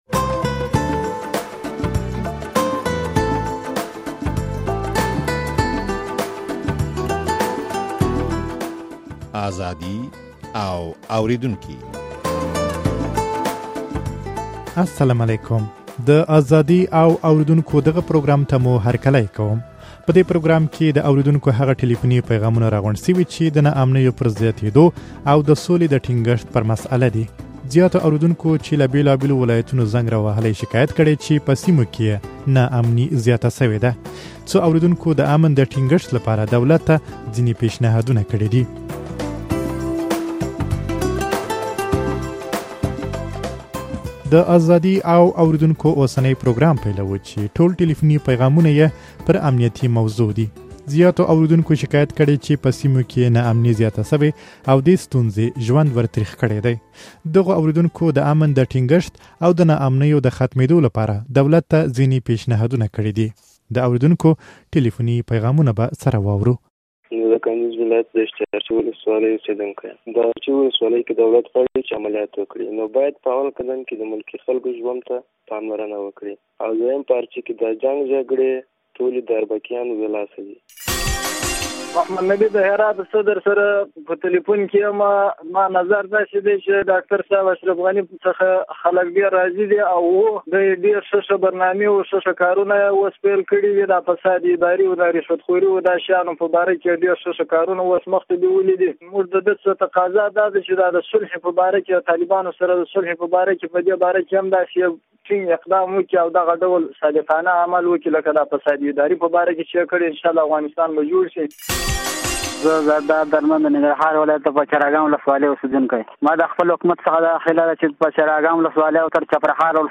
په دې پروګرام کې د اورېدونکو هغه ټليفوني پيغامونه راغونډ شوي، چې د ناامنيو پر زياتېدو او د سولې د ټينګښت پر مسئله دي.